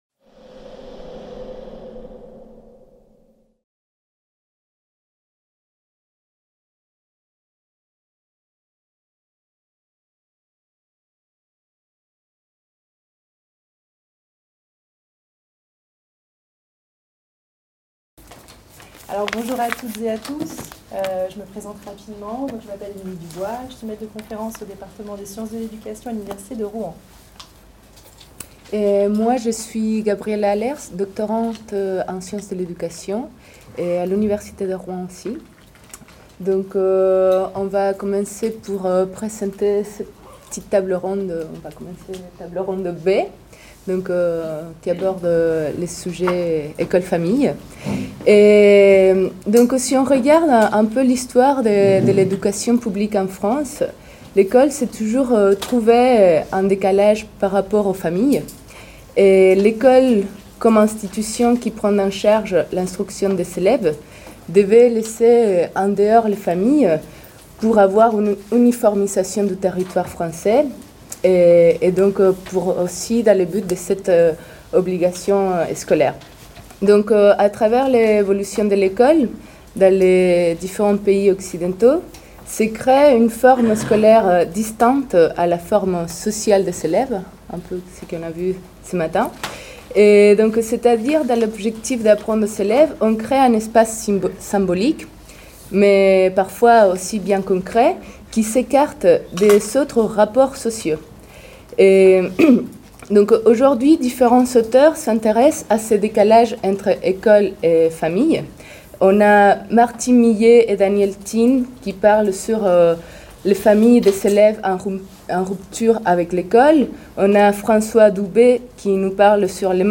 CIRNEF18 | 03 - Table ronde B : Continuité éducative : quels liens entre école et famille ?